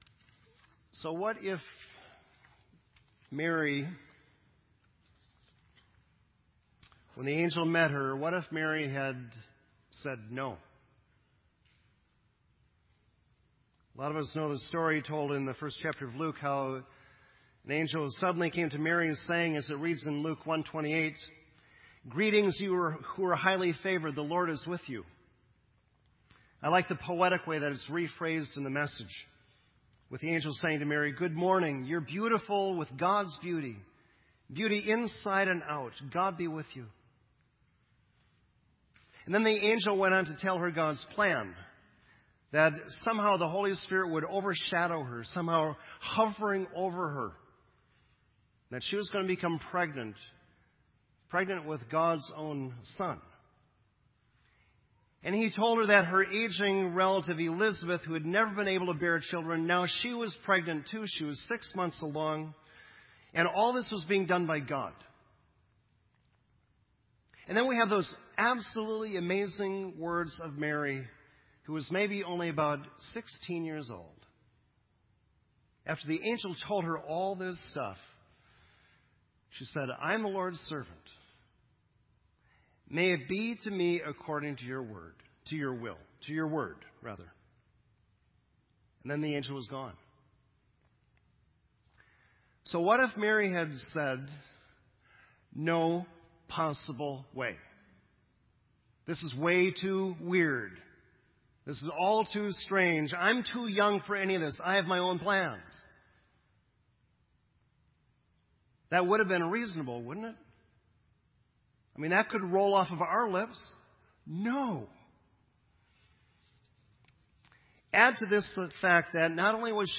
sermon
This entry was posted in Sermon Audio on December 15